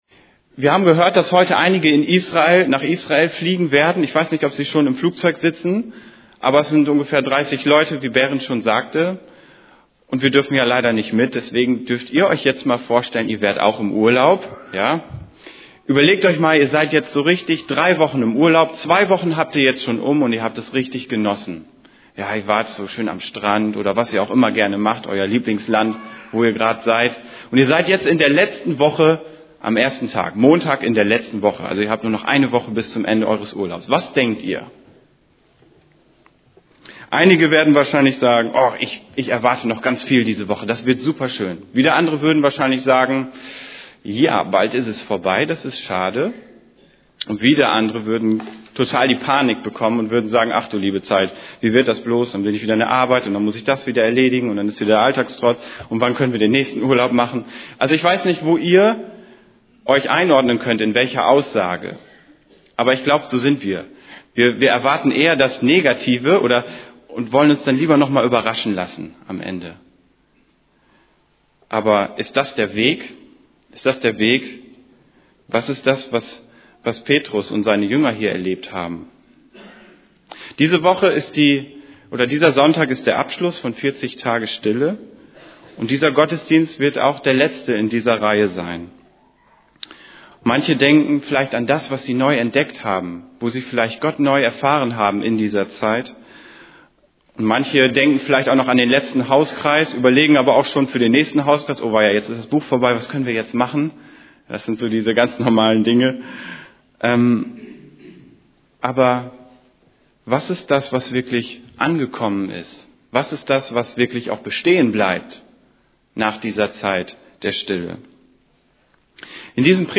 > Übersicht Predigten Gott zeigt uns, wo wir hingehören Predigt vom 14.